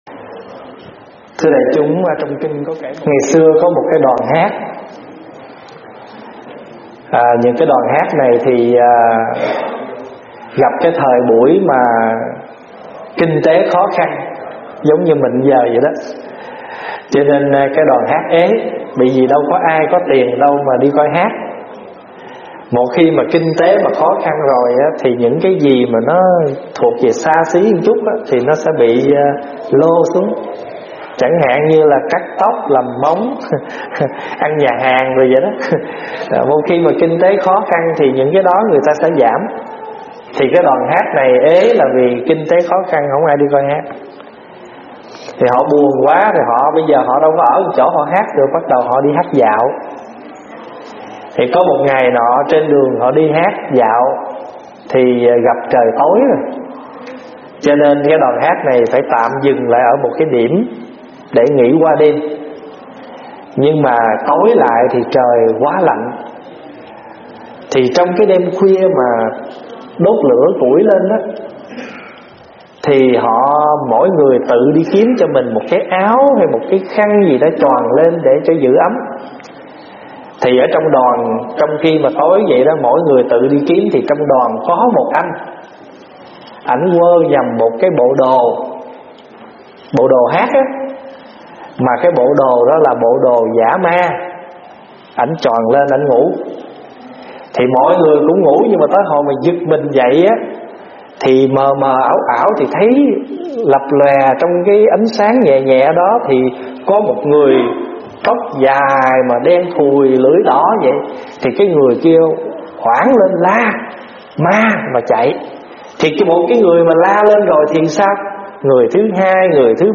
Nghe Mp3 thuyết pháp Phải học cách ứng xử khóe léo để được bình an